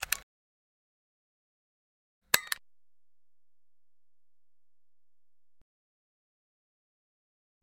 Звук клика кнопки диктофона